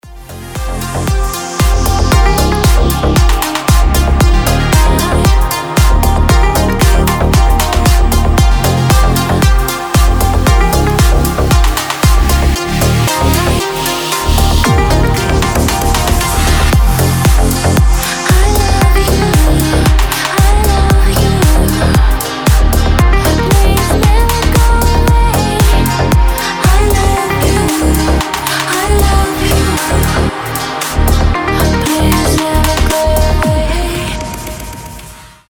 • Качество: 320, Stereo
красивые
deep house
чувственные
nu disco
Indie Dance